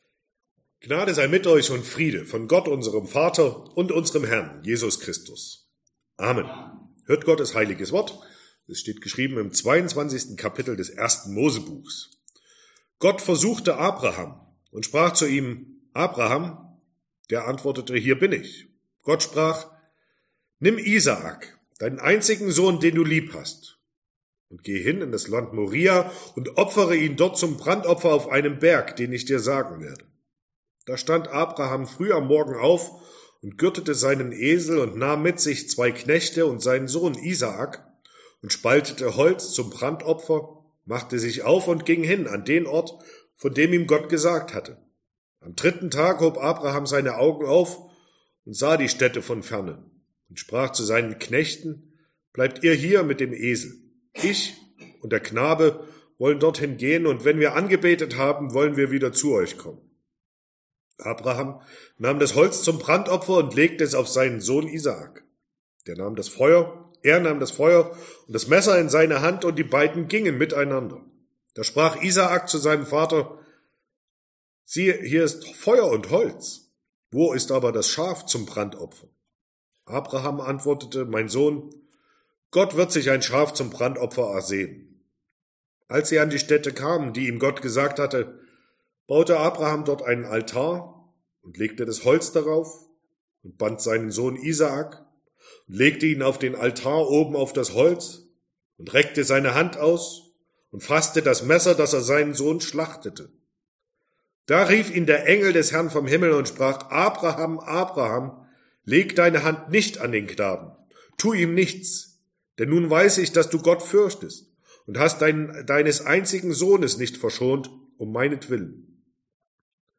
Sonntag nach Trinitatis Passage: 1. Mose 22, 1-13 Verkündigungsart: Predigt « 3.